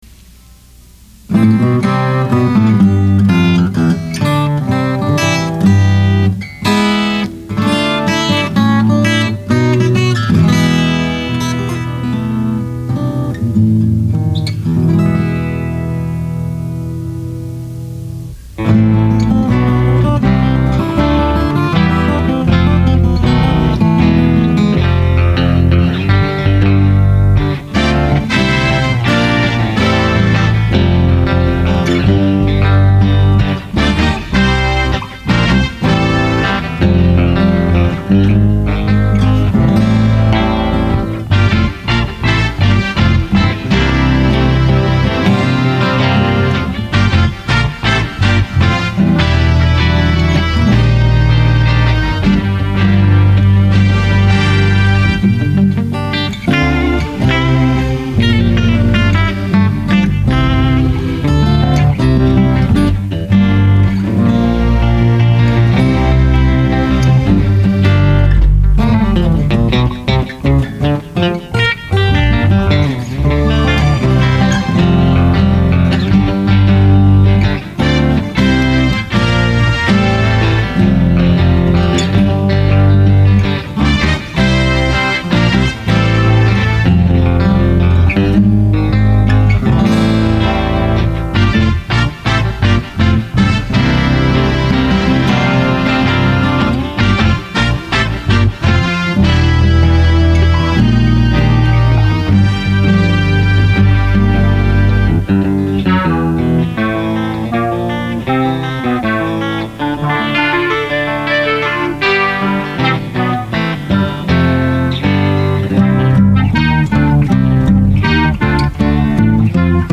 Alvarez acoustic and 67 Telecaster guitars
Korg M1 keyboards, bass
Drums
Conga